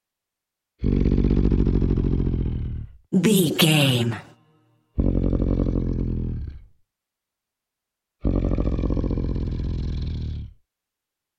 Monster growl snarl big creature x3
Sound Effects
scary
dark
angry